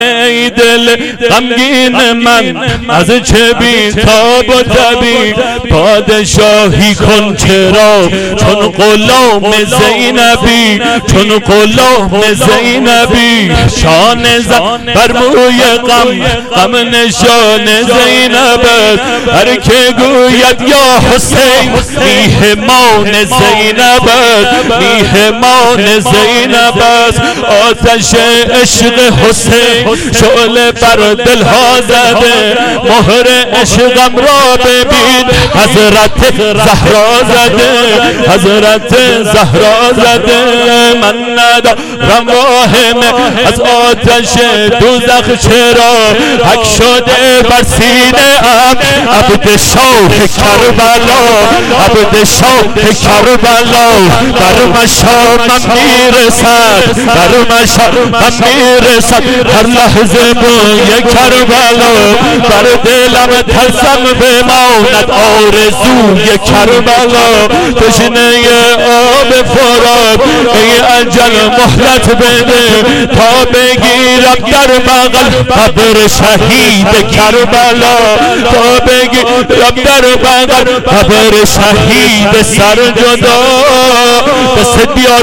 هیئت انصارالحسین سادات
محرم ۹۷ حضرت رقیه(س) سبک واحد